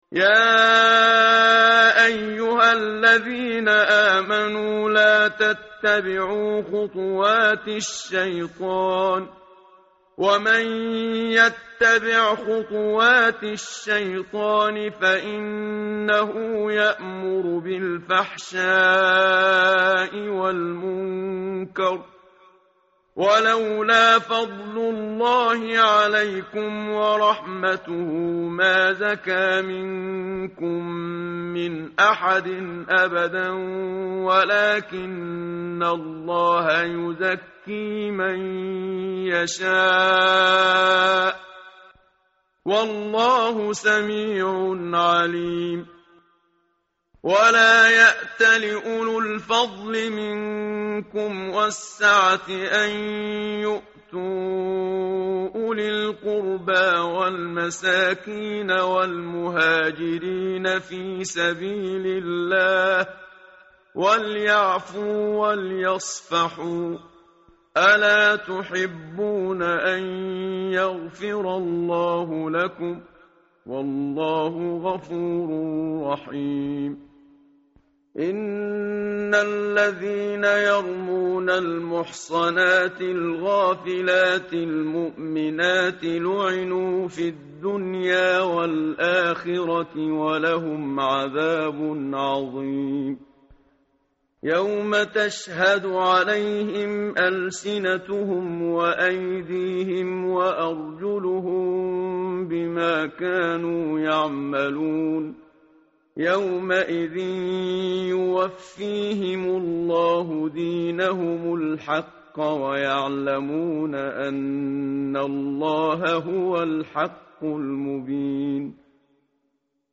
tartil_menshavi_page_352.mp3